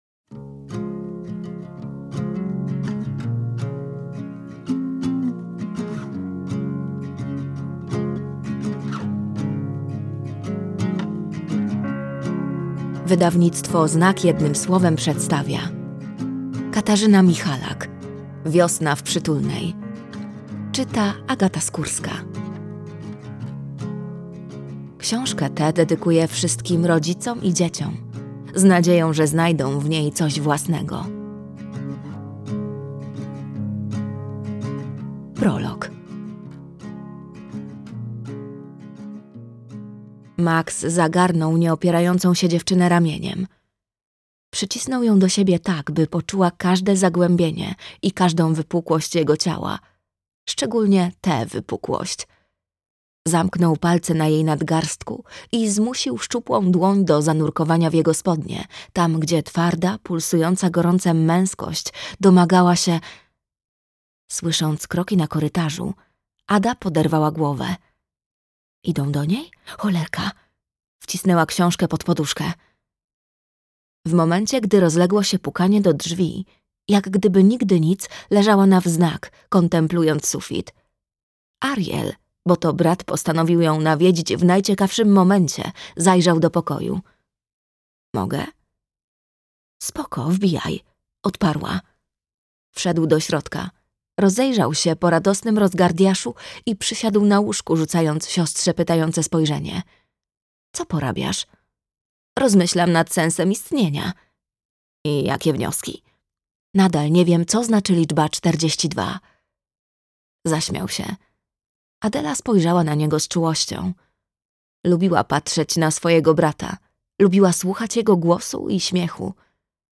Wiosna w Przytulnej - Katarzyna Michalak - audiobook